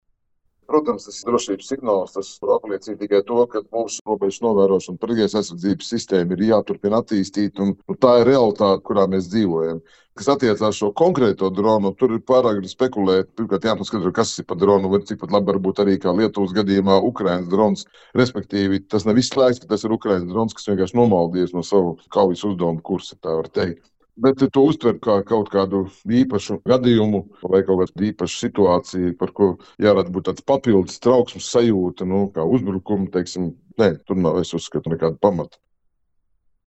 Saruna ar bijušo Nacionālo bruņoto spēku komandieri Raimondu Graubi